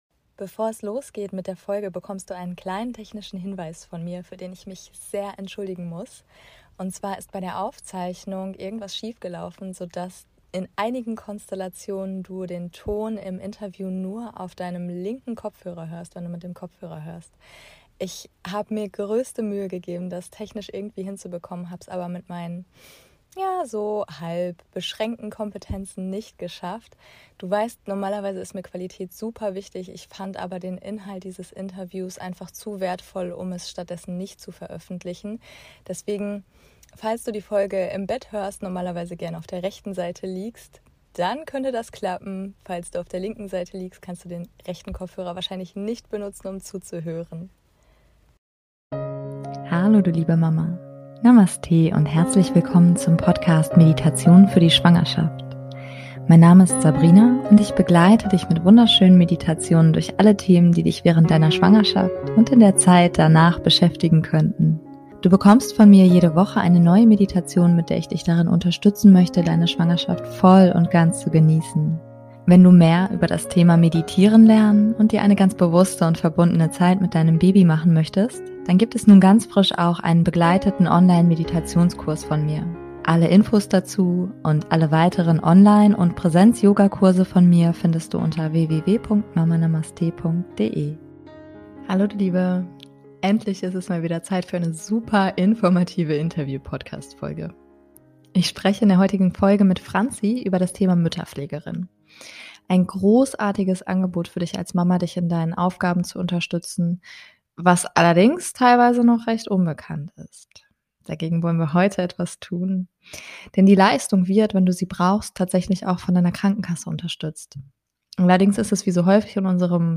Endlich mal wieder Zeit für eine super informative Interview Podcastfolge!